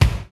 SZ KICK 04.wav